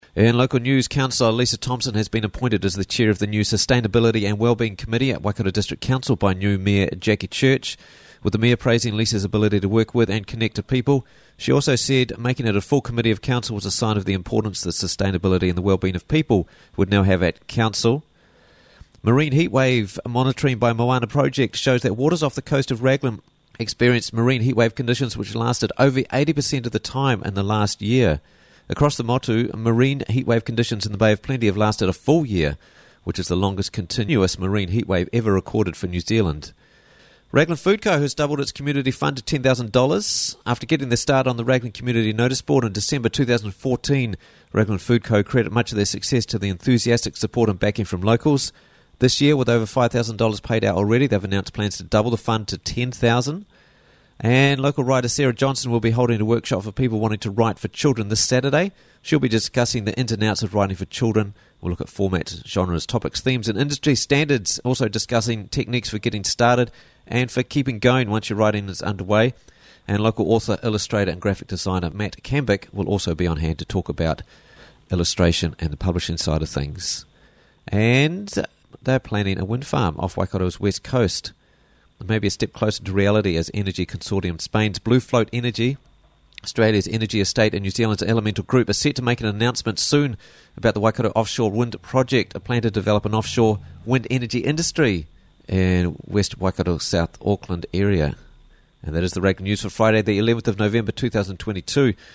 Raglan News Friday 11th November 2022 - Raglan News Bulletin